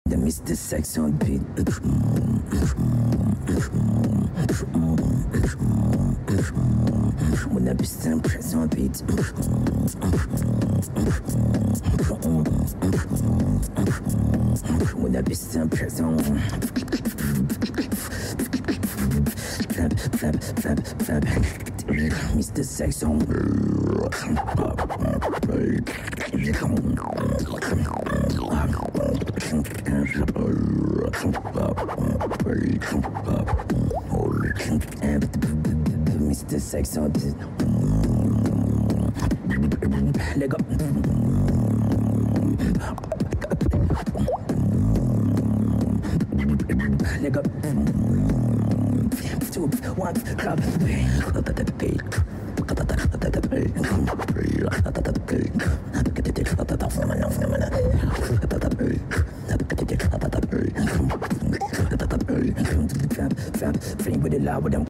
BEATBOXER
beatbox